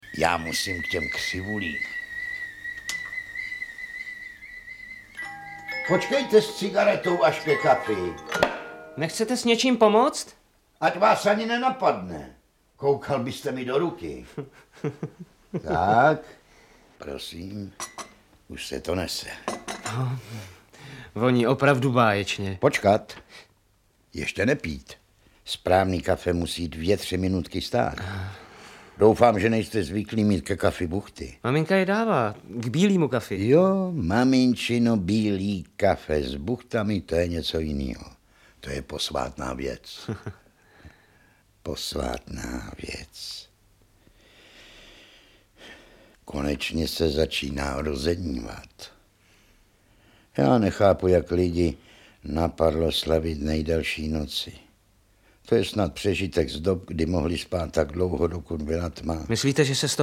Audiobook
Read: Ferdinand Krůta